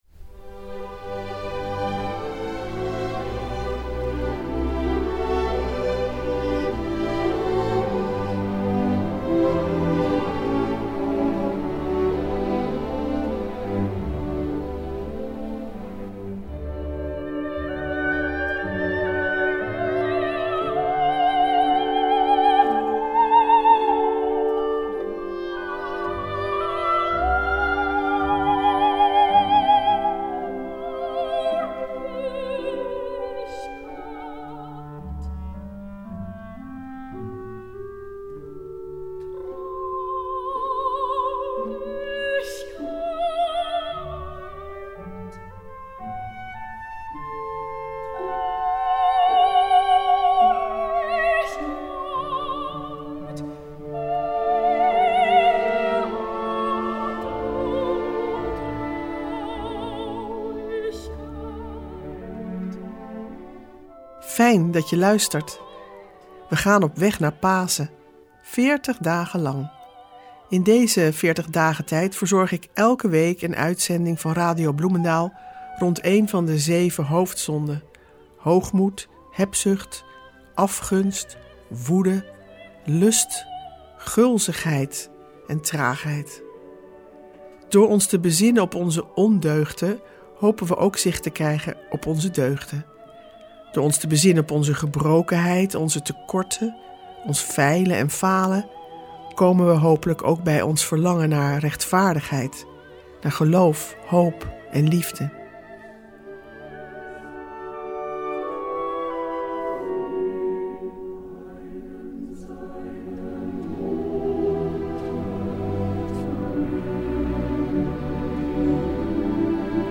Meditaties in de Veertigdagentijd